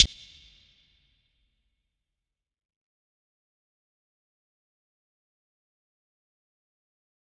Closed Hats
DMV3_Hi Hat 7.wav